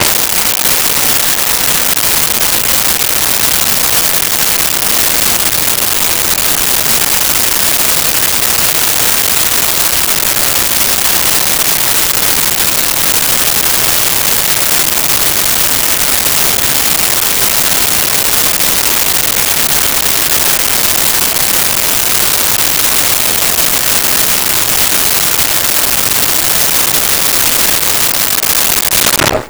Antique Car Start Idle Off
Antique Car Start Idle Off.wav